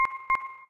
tickblue.ogg